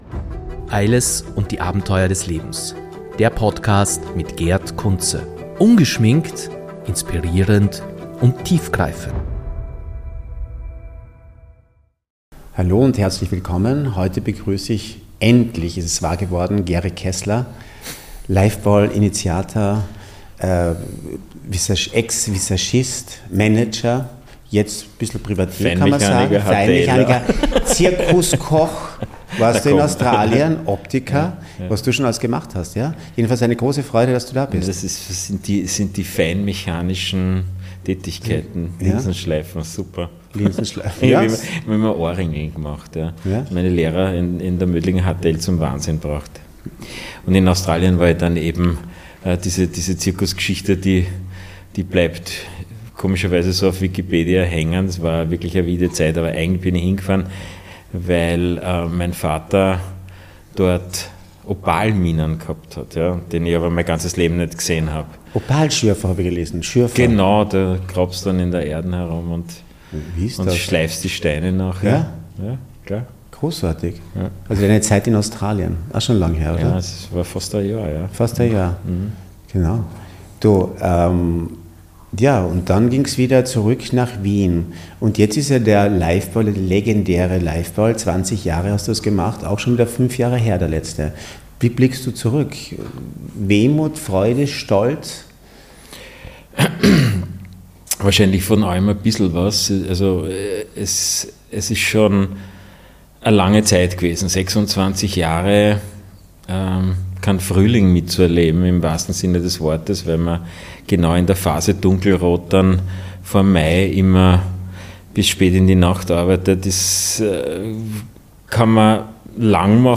In diesem inspirierenden und tiefgründigen Gespräch teilt Gery Keszler seine unglaublichen Erfahrungen und Geschichten aus seinem bewegten Leben.